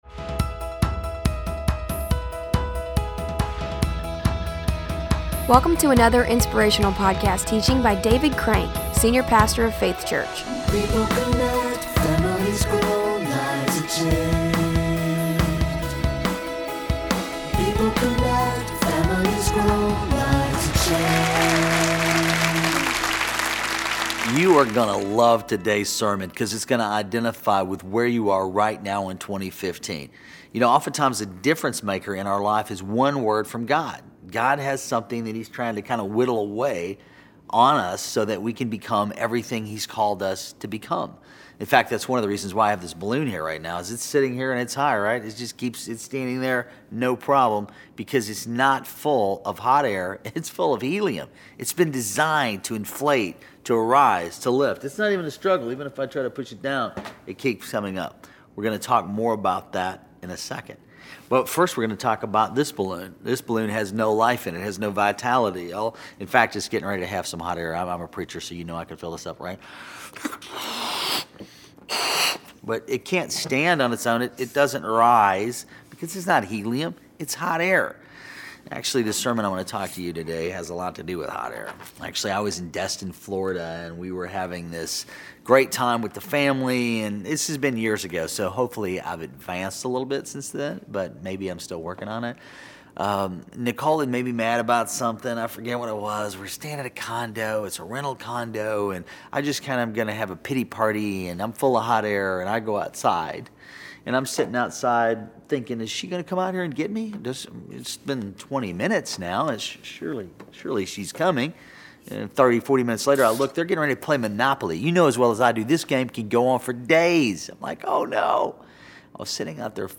In this teaching